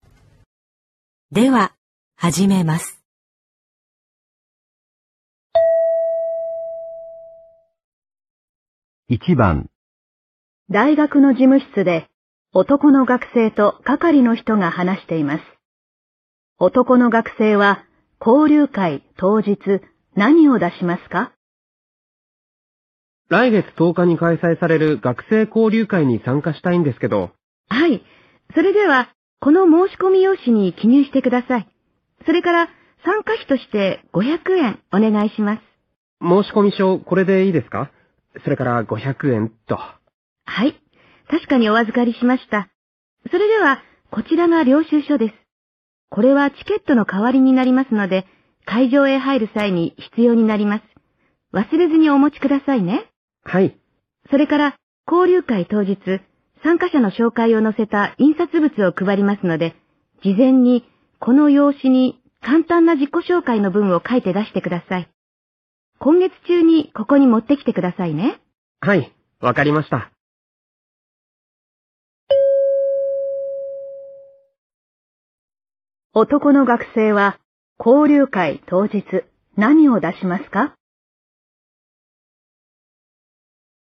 原文 大学で、男の学生と女の学生が話しています。